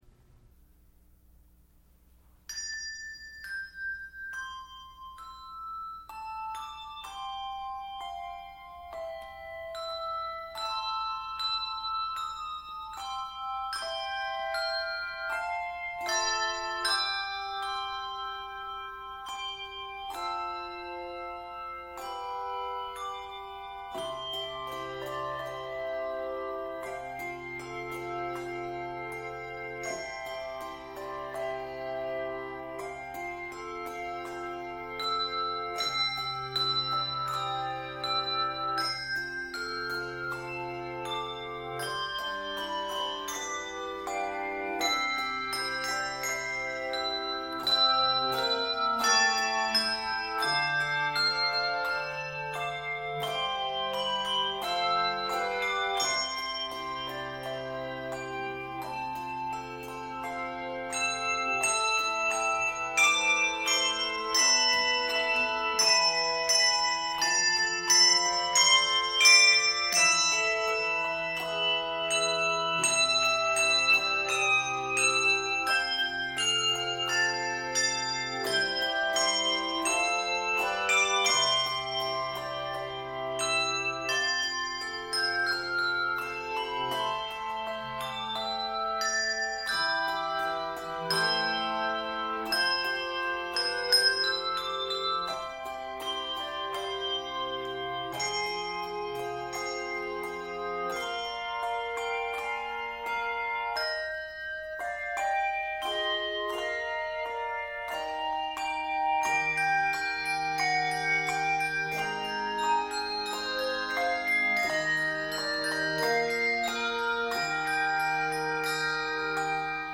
This thoughtful and lovely setting
Keys of C Major and Eb Major.